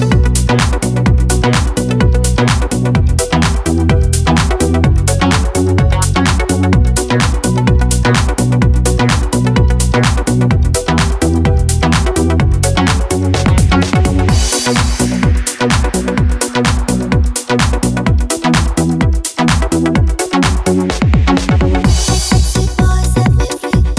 For Duet No Saxophone Pop